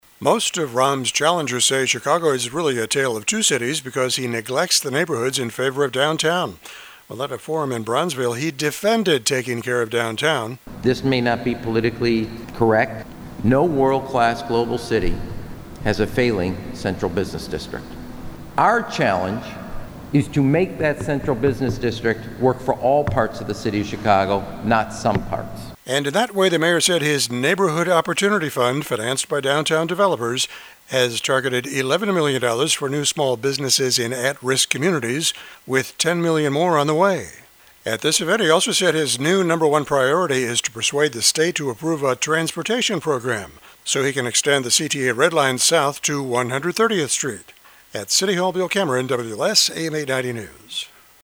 At a forum in Bronzeville, he defended taking care of downtown, “This may not be politically correct but no world class global city has a failing central business district. Our challenge is to make that central business district work for all parts of the City of Chicago not some parts.”